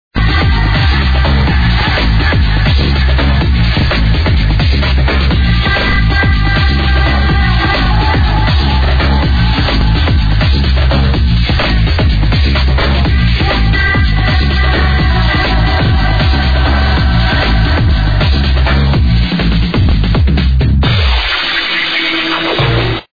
and EVIL ass bassline, ownage.